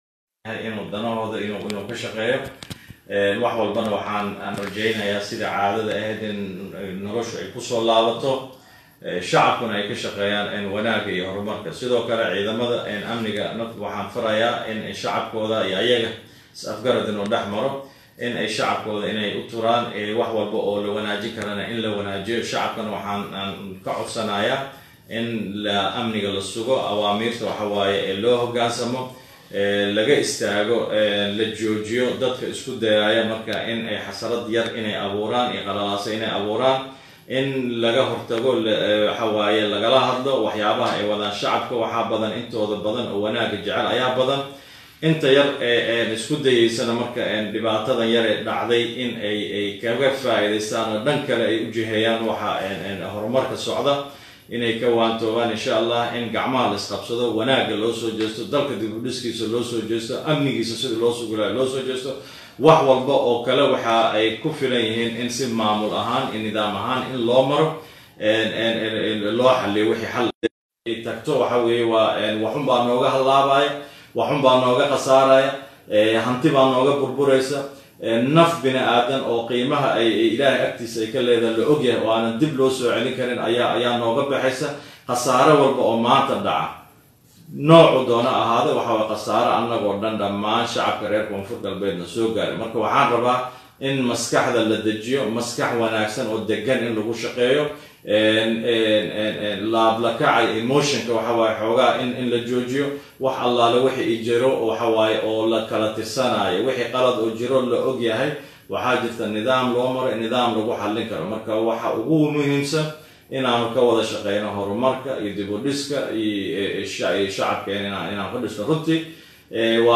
Baydhabo (Caasimada Online) – Sii-hayaha Madaxweynaha maamulka koonfur Galbeed ahna Gudoomiyaha Baarlamaanka maamulka Koonfur Galbeed oo warbaahinta kula hadlay Magaalada Baydhabo goor dhow ayaa ku baaqay in la joojiyo Banaanbaxyadda ka socda Magaalada Baydhabo.
Shirka-jaraid-ee-Gumooyaha-barlamaanka-KGS-1.mp3